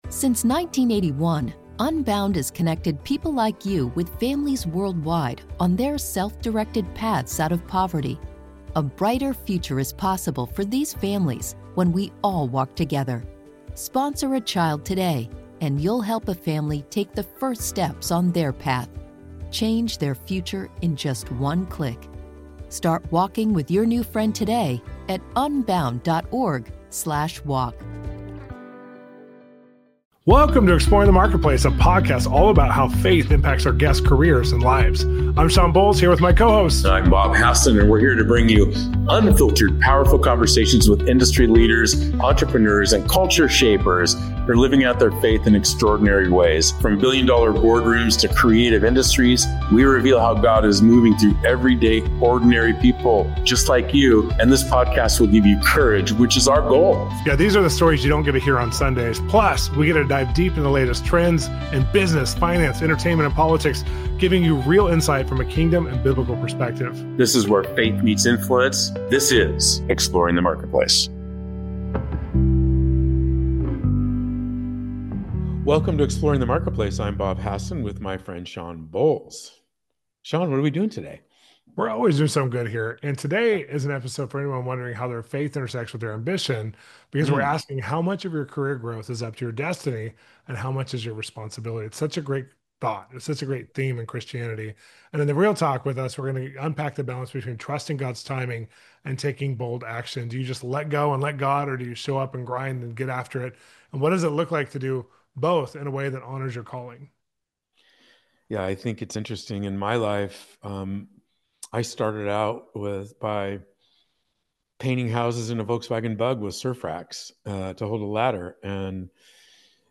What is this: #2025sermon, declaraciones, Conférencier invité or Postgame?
#2025sermon